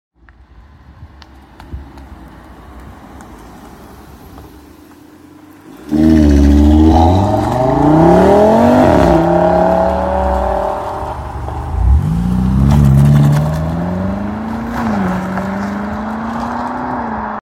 German cars rip out of sound effects free download
German cars rip out of car meet